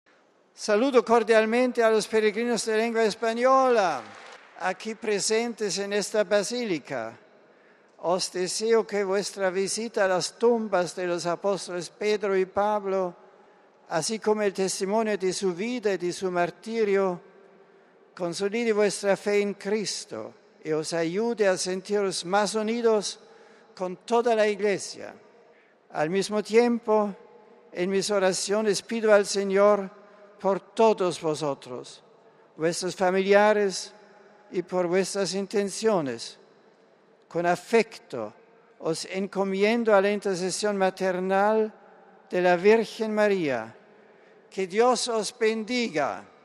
El Santo Padre ha aludido a los apóstoles Pedro y Pablo, en el saludo en español que ha pronunciado en la basílica vaticana.